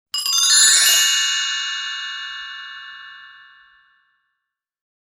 bell1
bell1.mp3